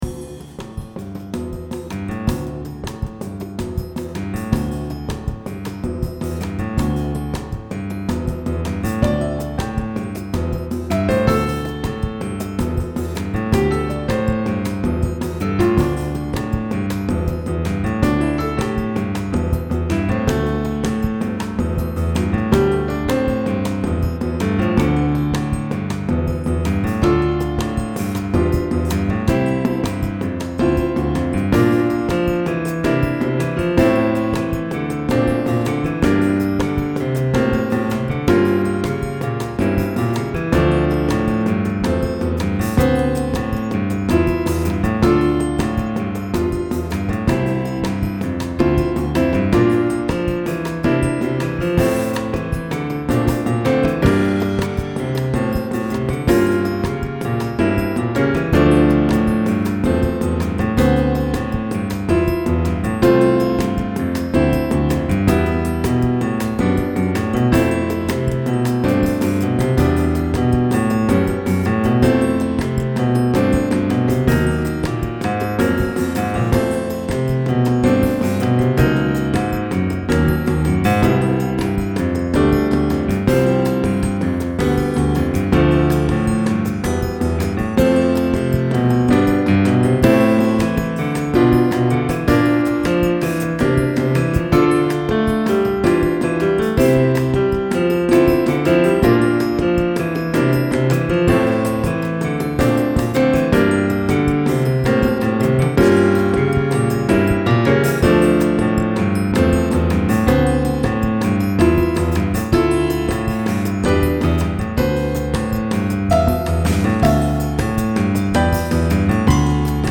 E-guitars and basses went directly into the mixer, acoustic guitars and percussion were recorded with the AKG microphones. Everything else is the sound of virtual instruments, played with the keyboard.
This is not easy listening, not background music, not music for multitaskers.
Latin